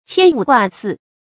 牵五挂四 qiān wǔ guà sì
牵五挂四发音